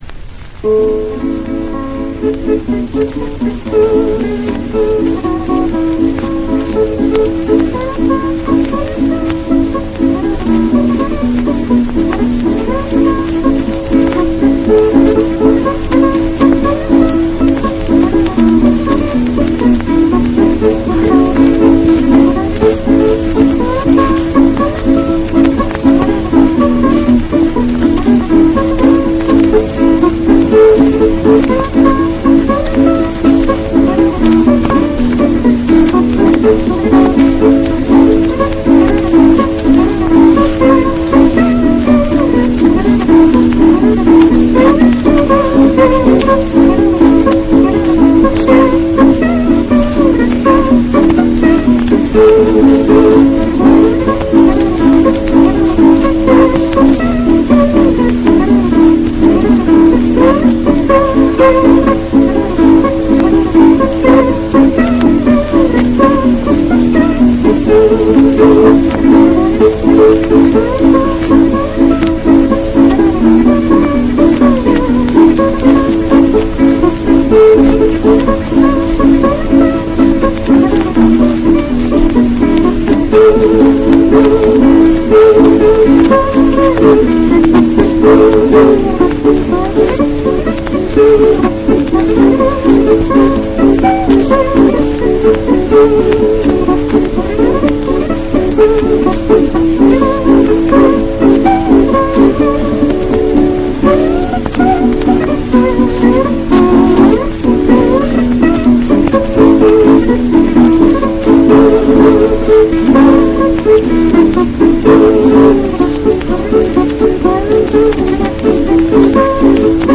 An early Hawaiian speciality